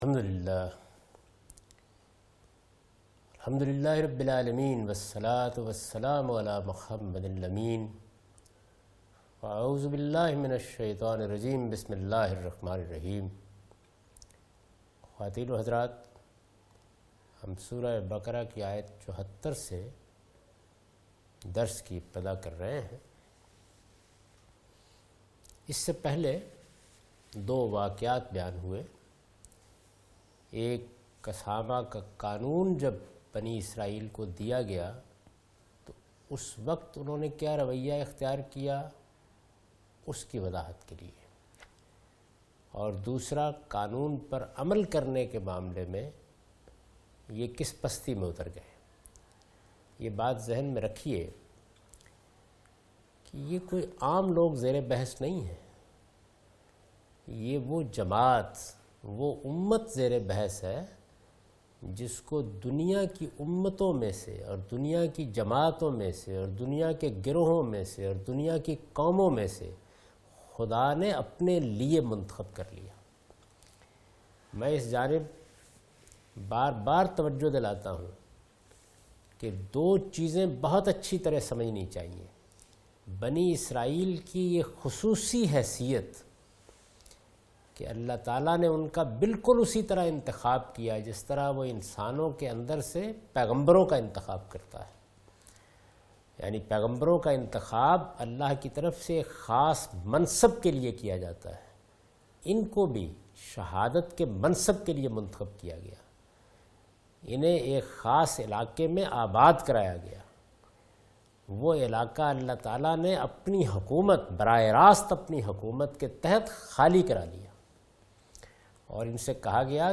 Surah Al-Baqarah - A lecture of Tafseer-ul-Quran – Al-Bayan by Javed Ahmad Ghamidi. Commentary and explanation of verse 74,75,76 and 77 (Lecture recorded on 3rd Oct 2013).